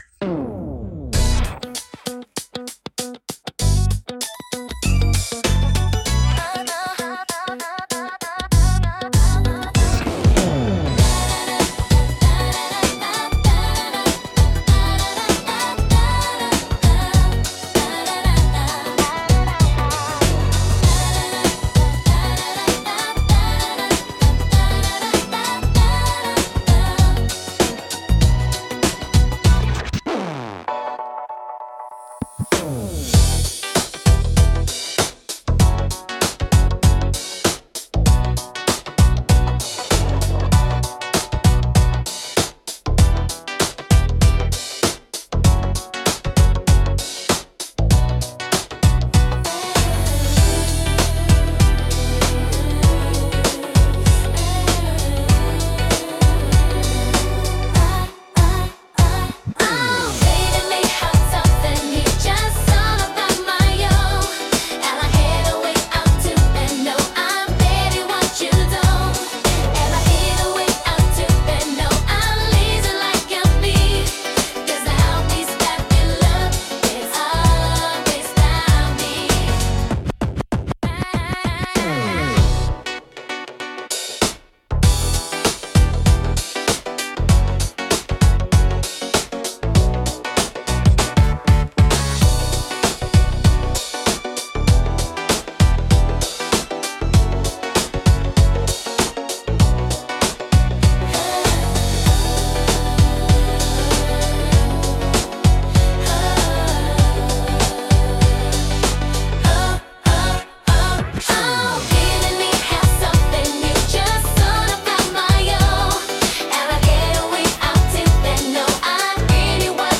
聴く人に心地よいリズム感と温かみを届ける、優雅で感性的なジャンルです。